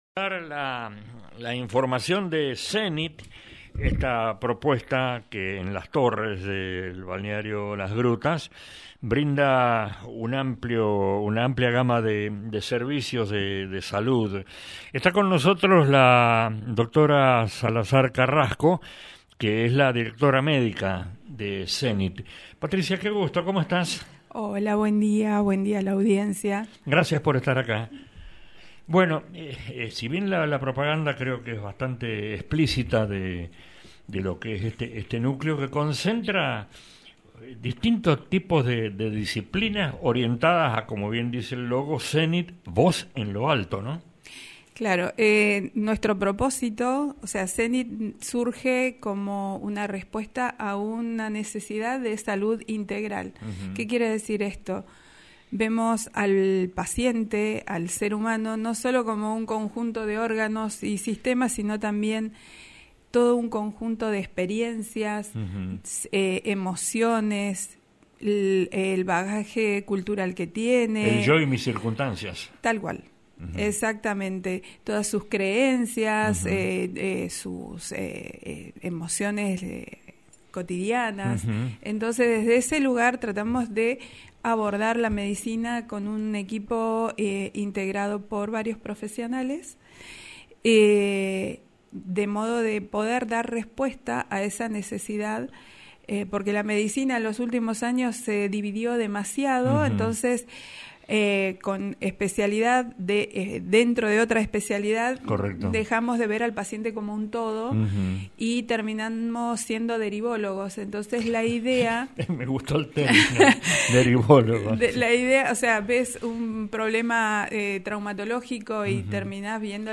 AUDIO ENTREVISTA